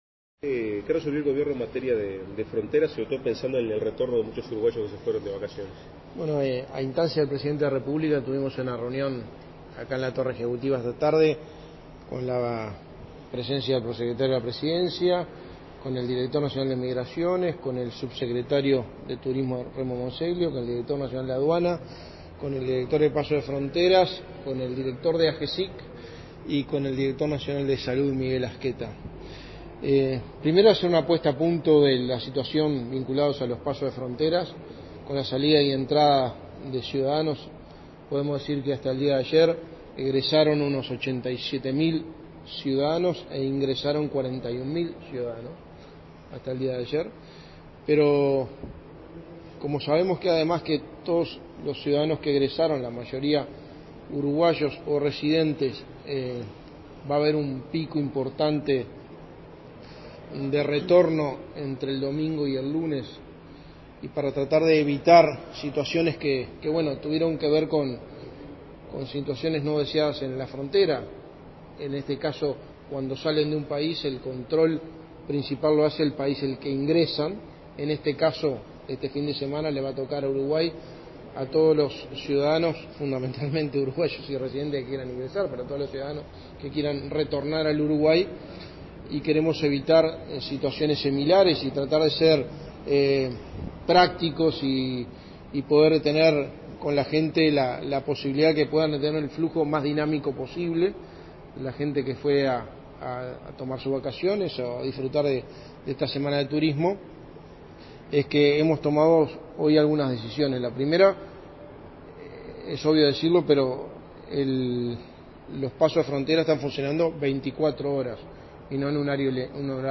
Declaraciones a la prensa del secretario de Presidencia, Álvaro Delgado